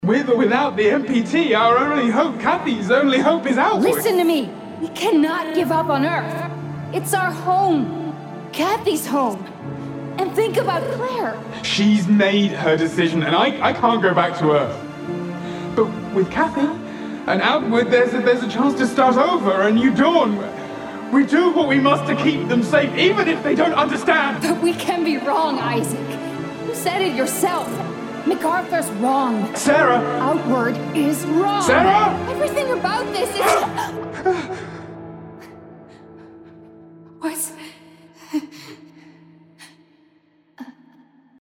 English (British)
Video Games
Custom-built home studio